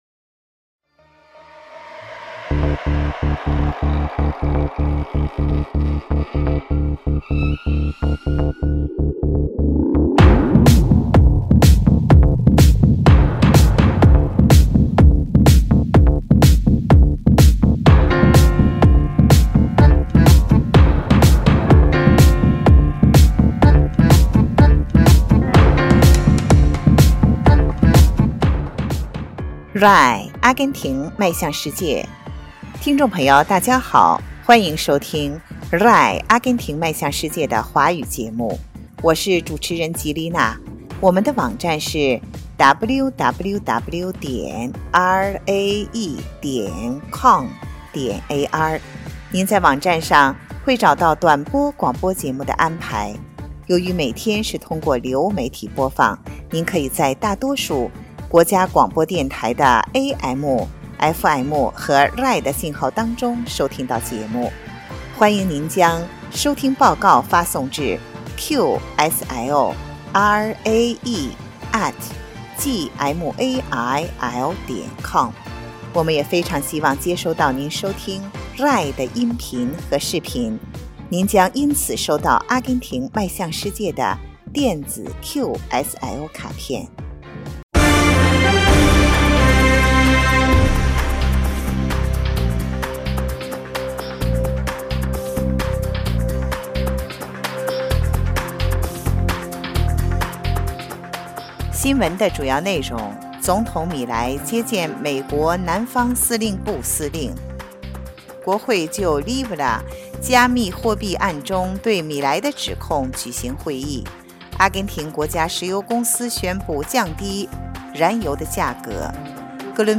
Noticias